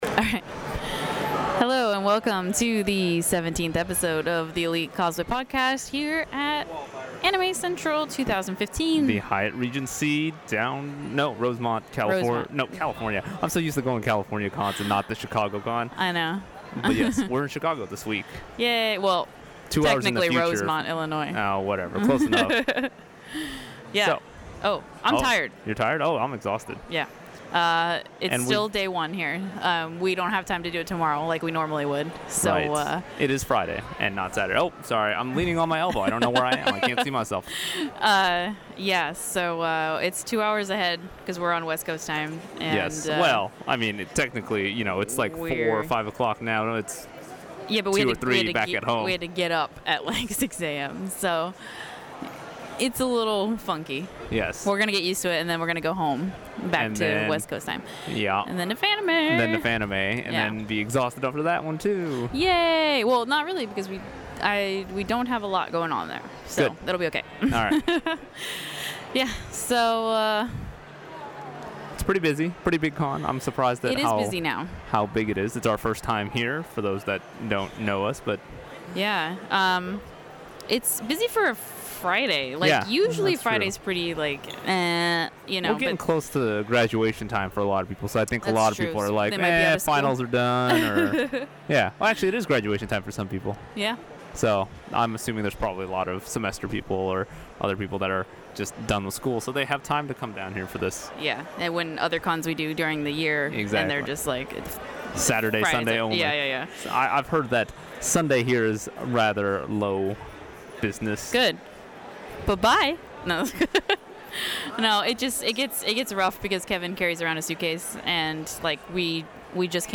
This episode of the podcast is being brought to you from Anime Central 2015! We talk about our time so far at Acen, photoshoots and Chicago.